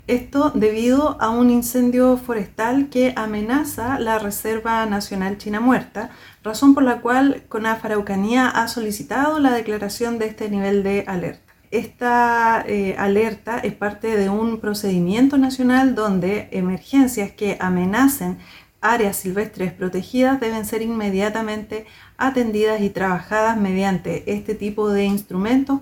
La activación de la alerta roja comunal para Melipeuco se generó por la amenaza que este incendio representa para la Reserva Nacional China Muerta, según indicó la directora regional de Onemi Araucanía, Janet Medrano.